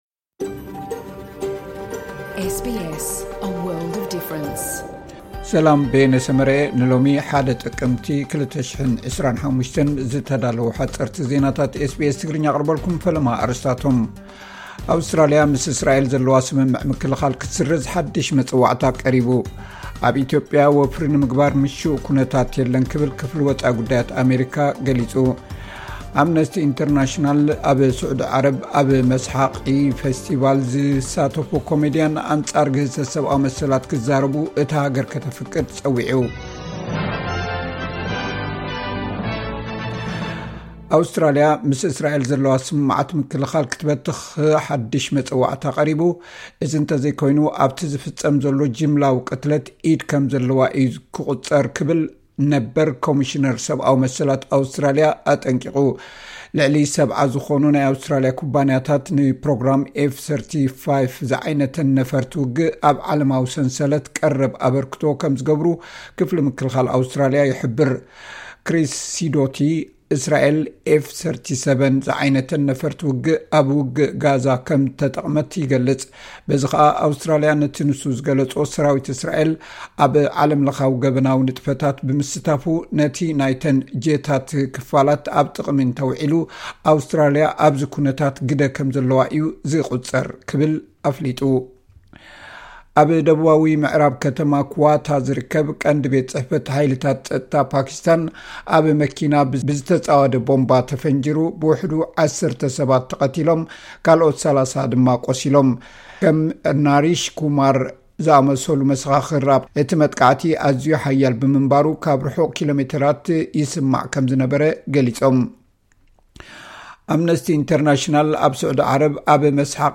ኣውስትራሊያ ምስ እስራኤል ዘለዋ ስምምዕ ምክልኻል ክትስርዝ ሓድሽ መጸዋዕታ ቐሪቡ...(ሓጸርቲ ዜናታት ኤስ ቢ ኤስ ትግርኛ)